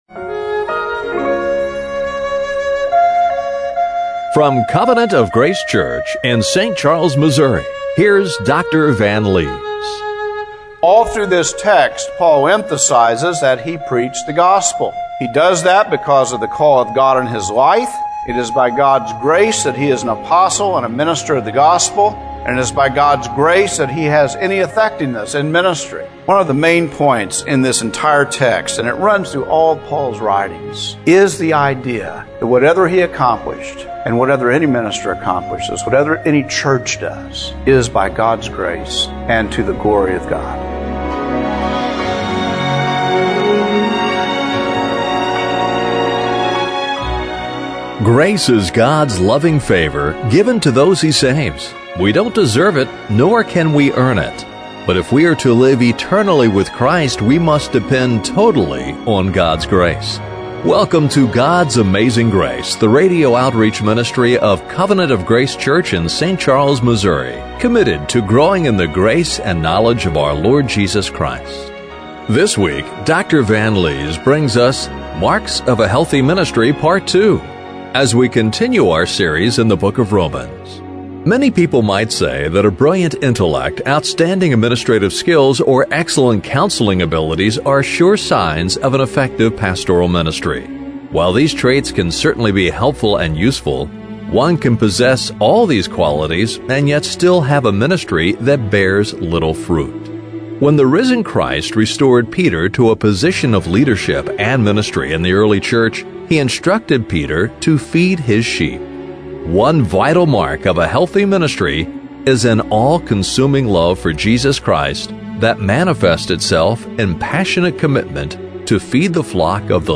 Romans 15:15-19 Service Type: Radio Broadcast Do you have any of the vital marks of a healthy ministry?